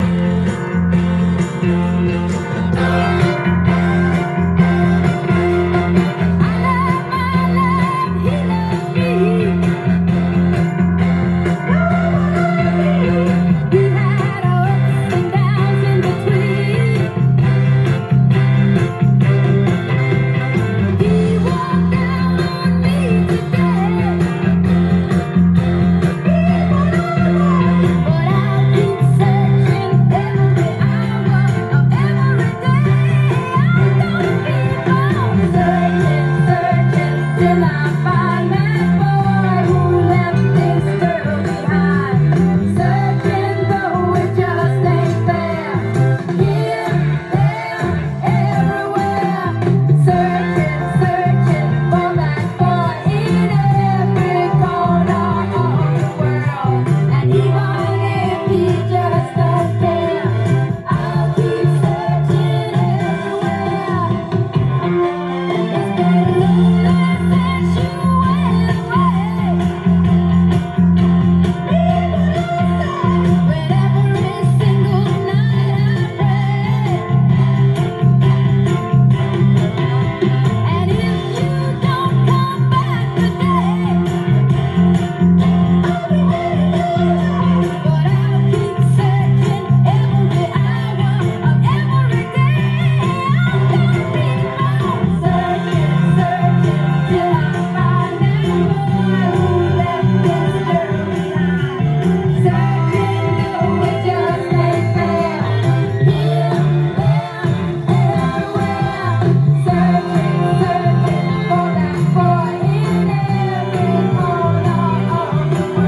ジャンル：SOUL
店頭で録音した音源の為、多少の外部音や音質の悪さはございますが、サンプルとしてご視聴ください。
音が稀にチリ・プツ出る程度